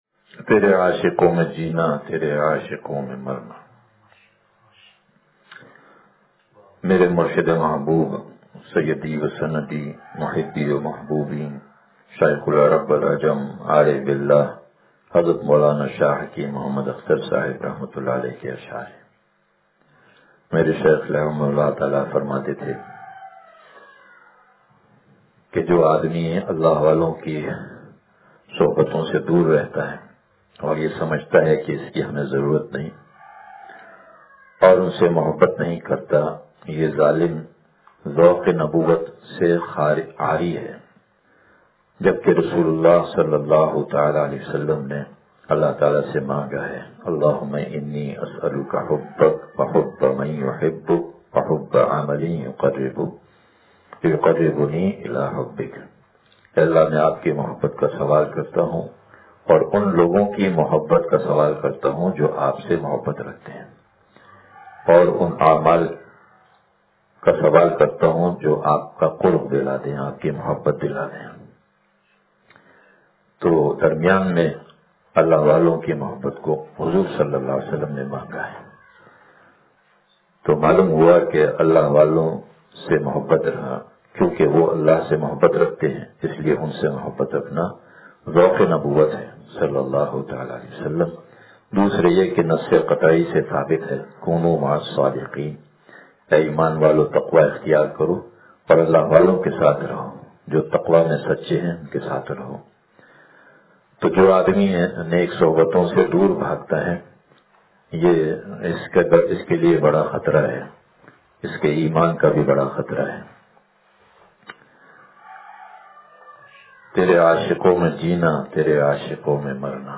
ترے عاشقوں میں جینا ترے عاشقوں میں مرنا – مجلس بروز اتوار